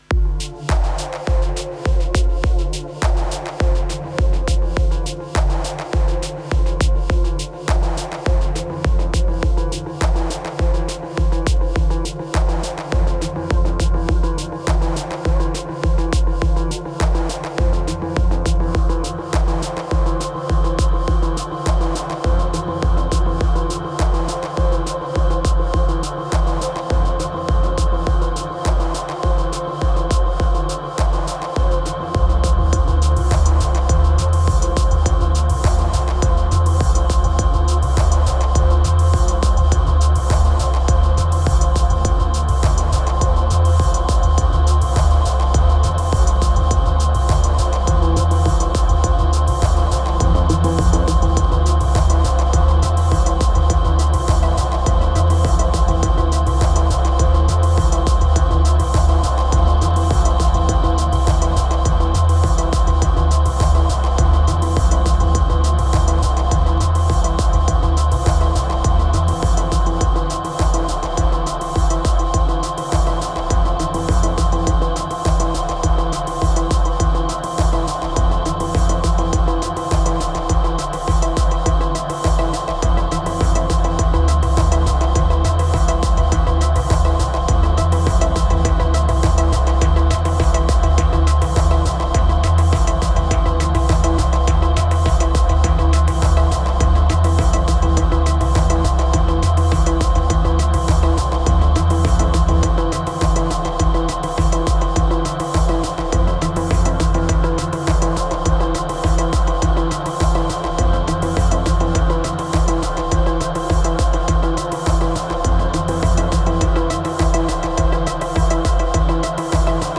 Laidback unity.